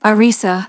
synthetic-wakewords
ovos-tts-plugin-deepponies_Celestia_en.wav